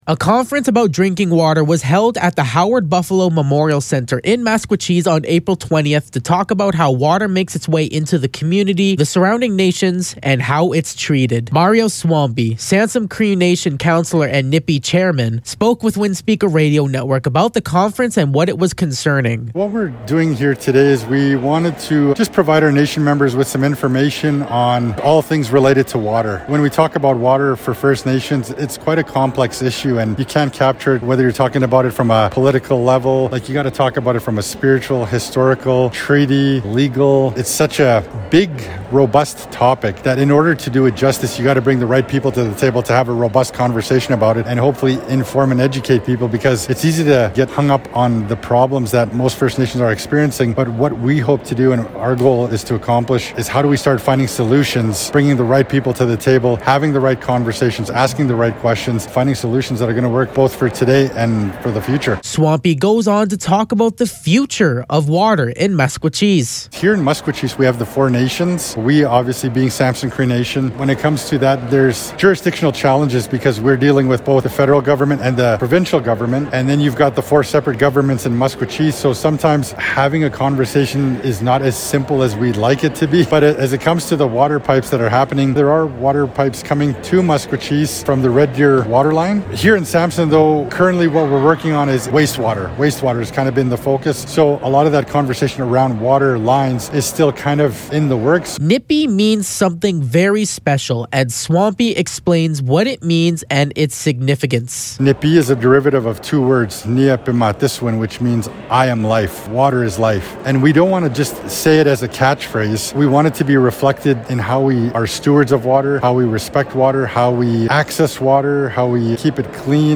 A conference about drinking water was held at the Howard Buffalo Memorial Centre in Maskwacis yesterday on how water makes its way into the surrounding four nations and the treatment process behind it all.
Mario Swampy, Samson Cree Nation Councilor and Nipiy Chairman, spoke at the conference to provide information and closure to locals about "all things water."